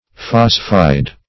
Search Result for " phosphide" : The Collaborative International Dictionary of English v.0.48: Phosphide \Phos"phide\ (f[o^]s"f[imac]d), n. (Chem.) A binary compound of phosphorus.